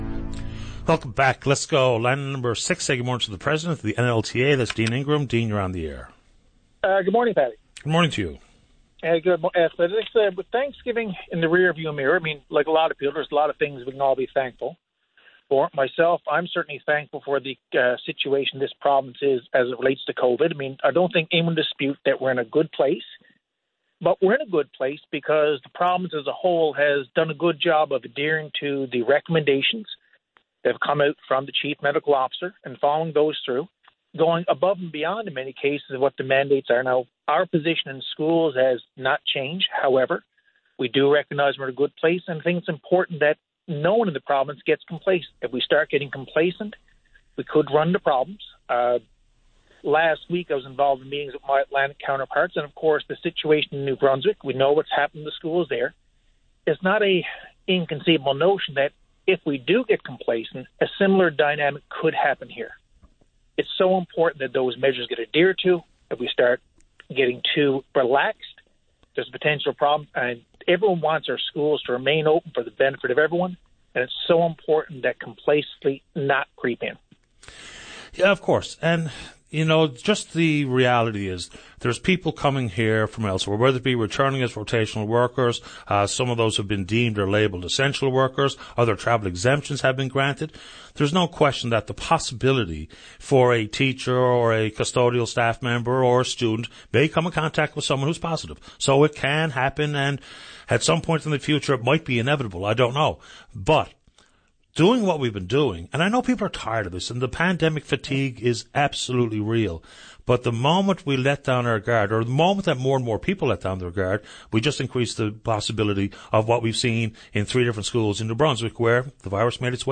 Media Interview - VOCM Open Line Oct 13, 2020